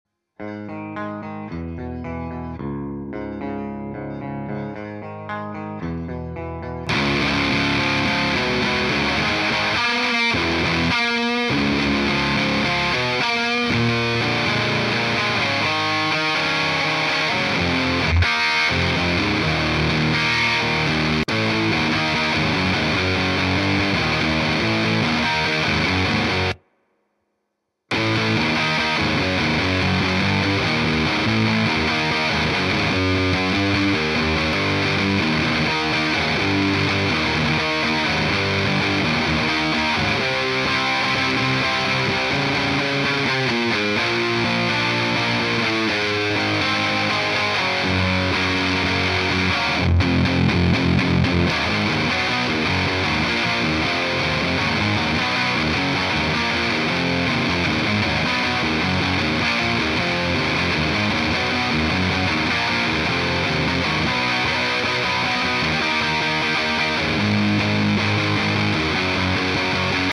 riffs